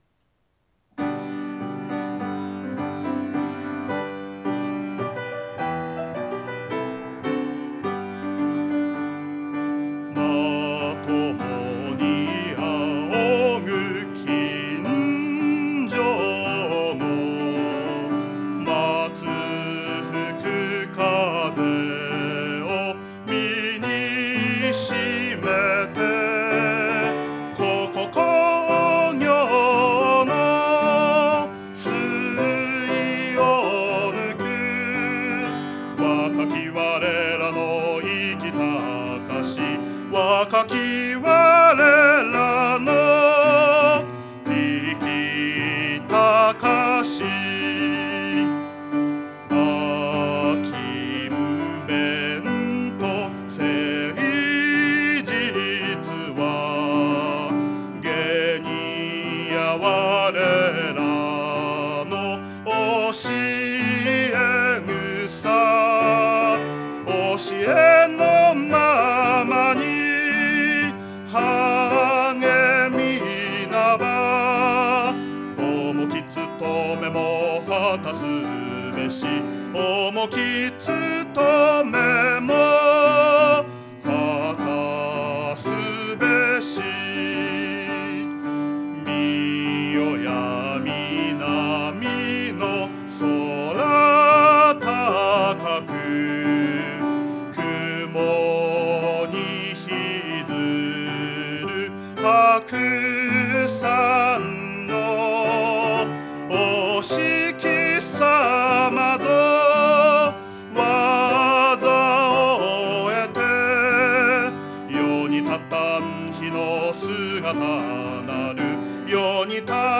校歌
校歌(ピアノ) 校歌(歌唱有)